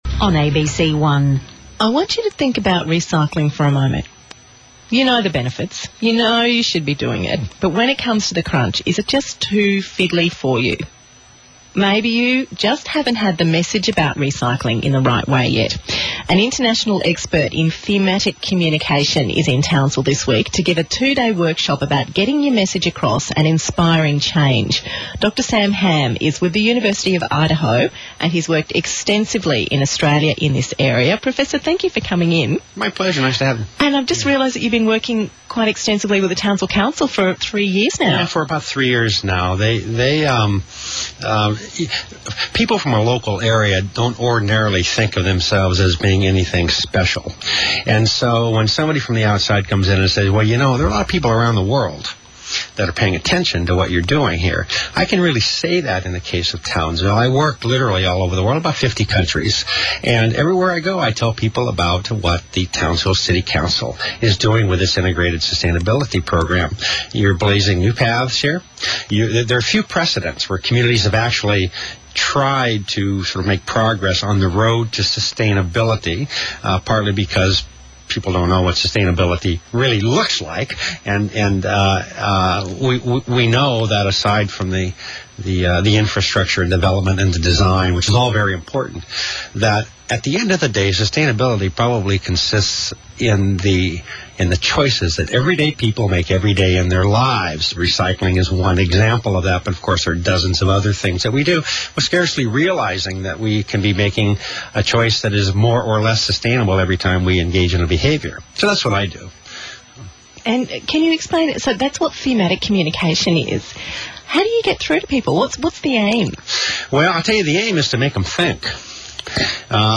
ABC Radio Talk